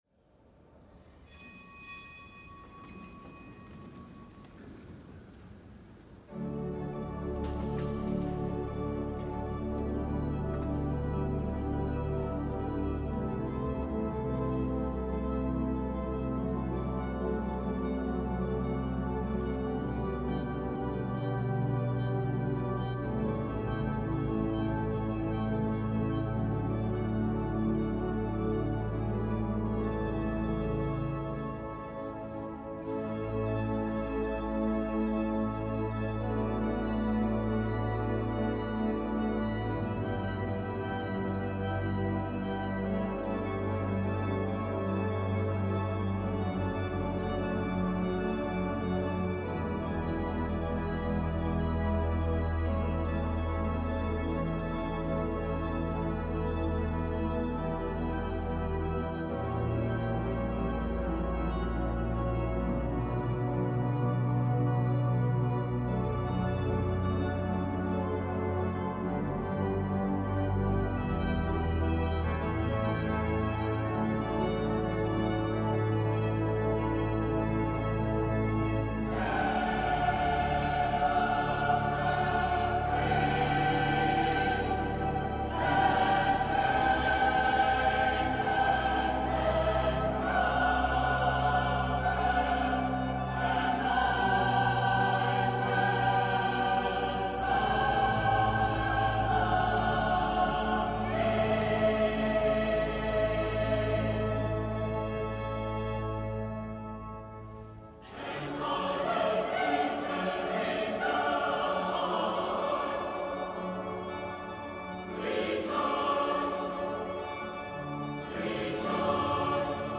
Kapitelsamt am dritten Adventssonntag